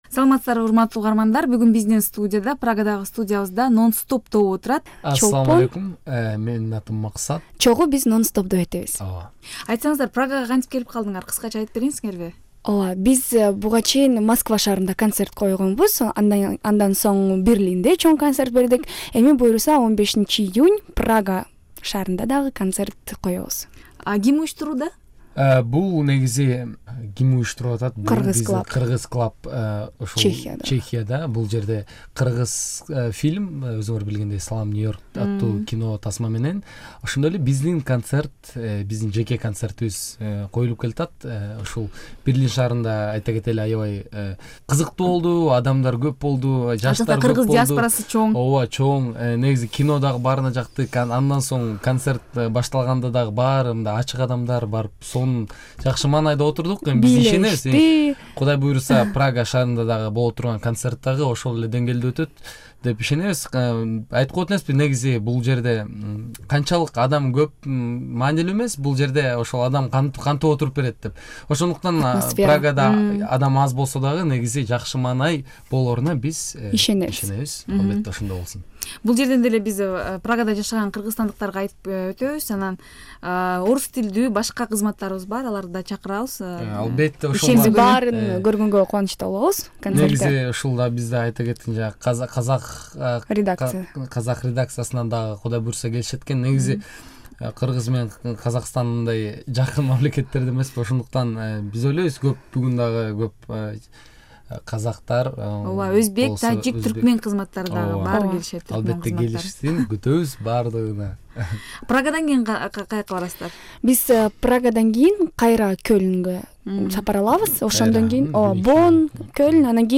Прага студиясында